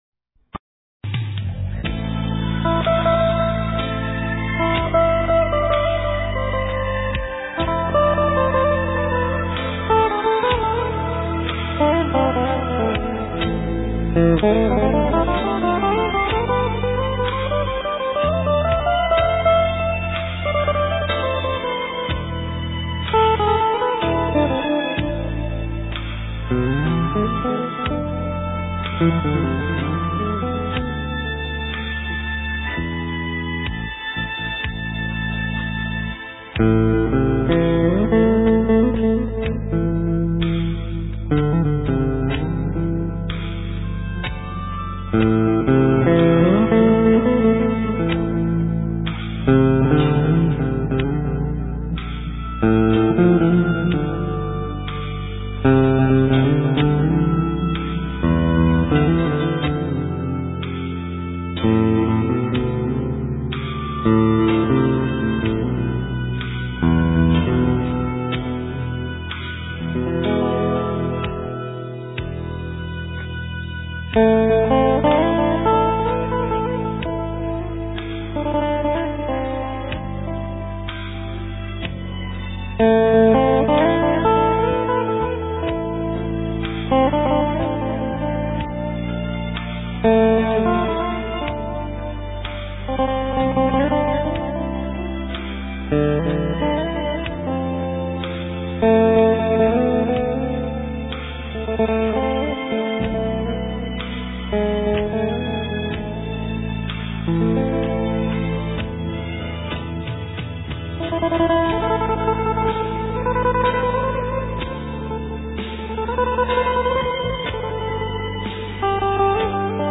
Ca sĩ: Không lời